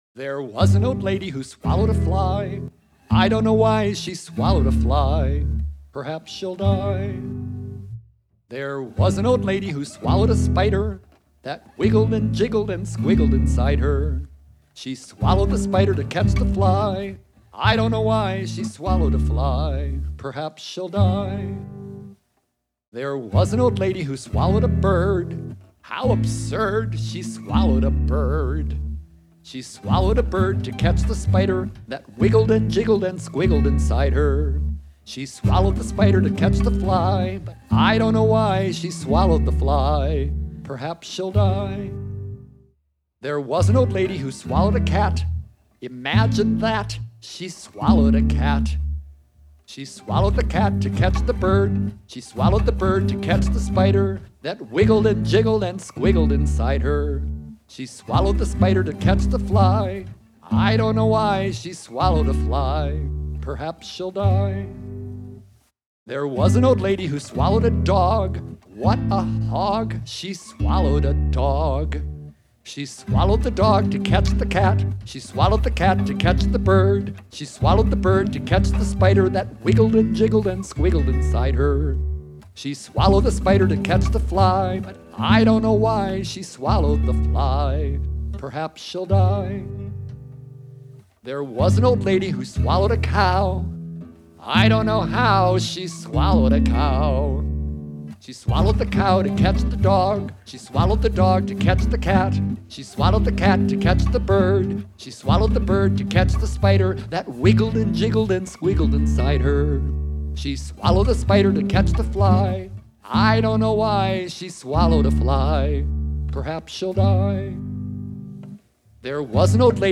FREE MP3 Download - Full Performance
Prepare to be amazed by one old lady’s bizarre and enormous appetite in this delightfully silly folk song, part of a series of captivating picture books based on beloved songtales from the great American folk tradition.